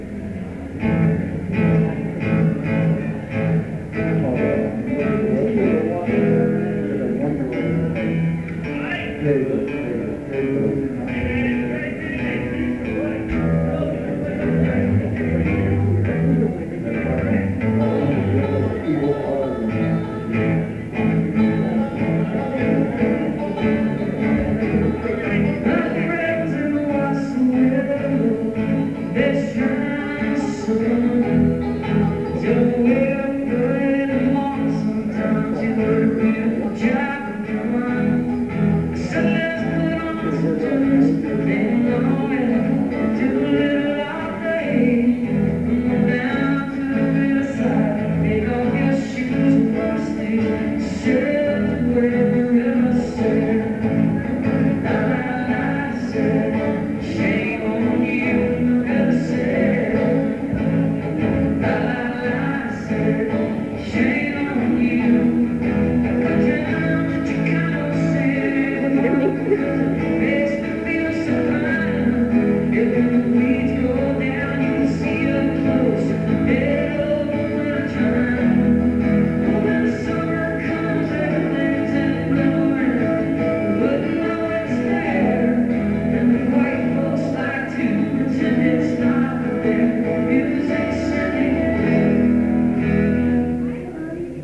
soundcheck